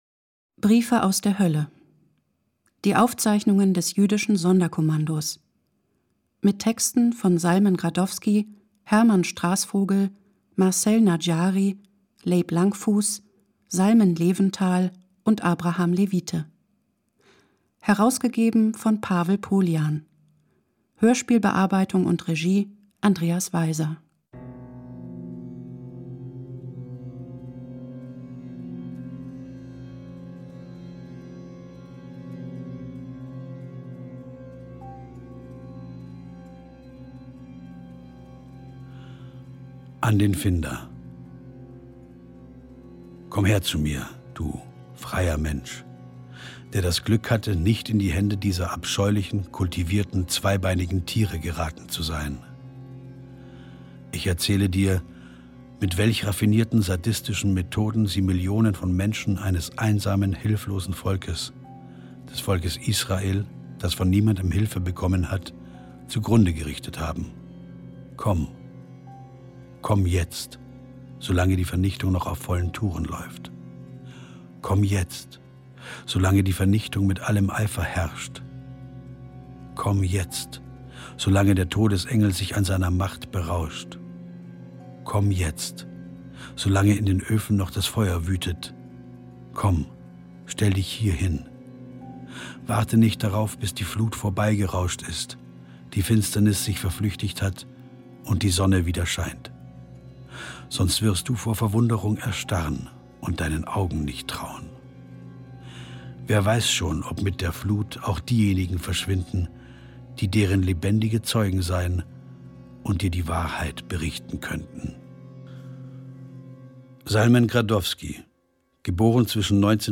Hörspiel von Andreas Weiser mit Wolfram Koch u.v.a. (2 CDs)